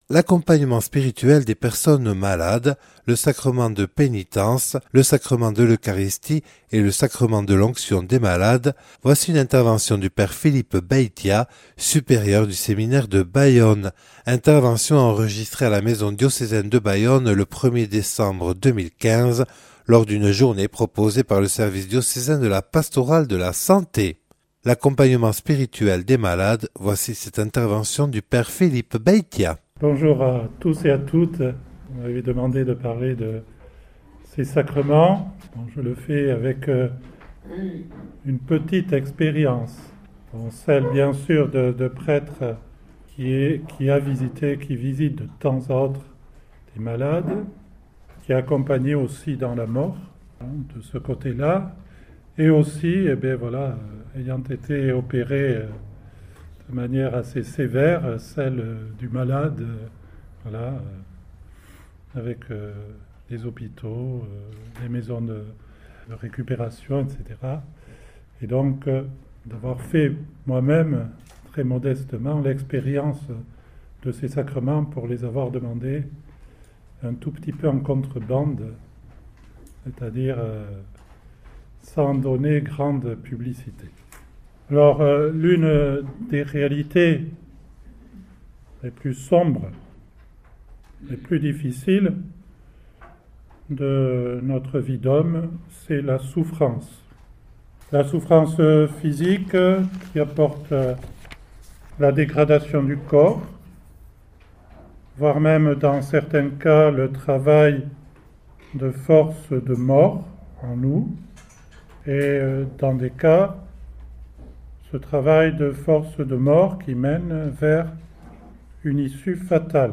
Enregistré le 01/12/2015 lors d'une journée de la Pastorale de la santé